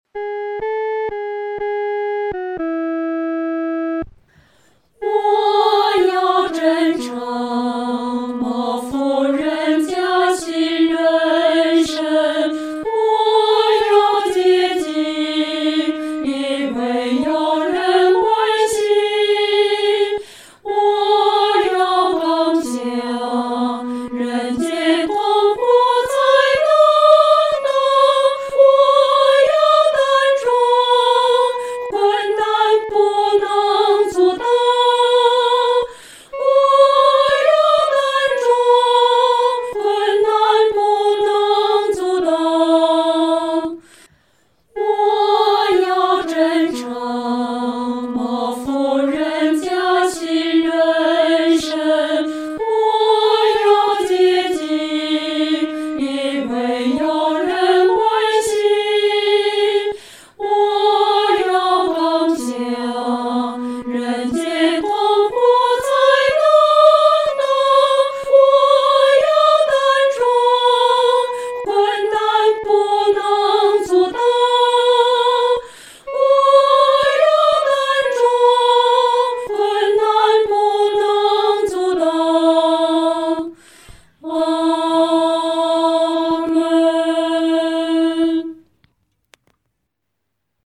女高音